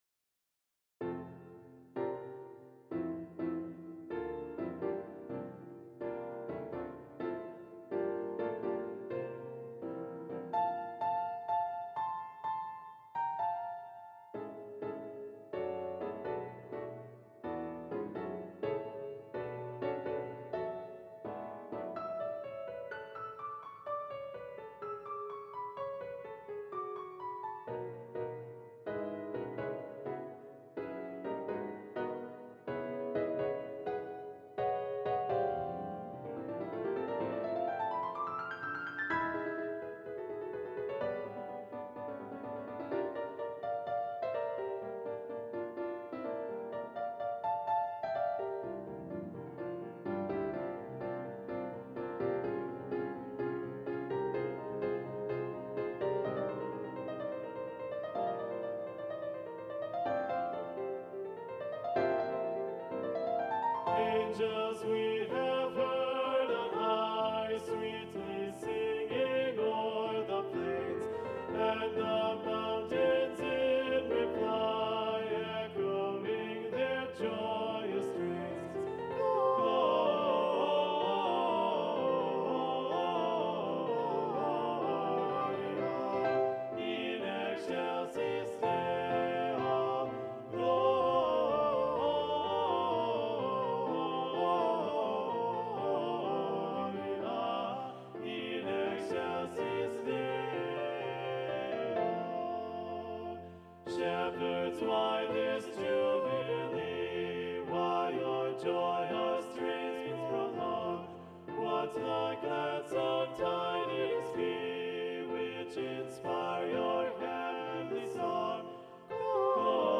Video Only: Angels We Have Heard on High - Descant